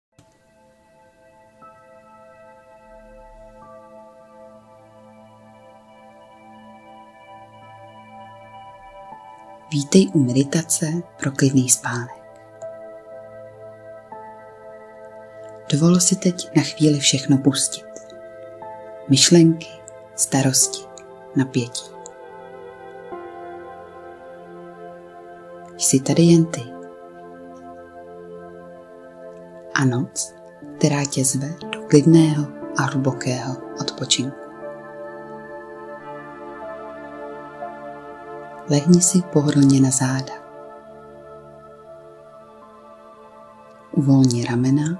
Nahraju pro vás jemnou meditační nebo afirmativní nahrávku (ženský hlas)
• Nahrávku ženského hlasu (klidného, jemného charakteru)
Nahrávám v domácím prostředí s důrazem na čistotu zvuku a příjemný přednes.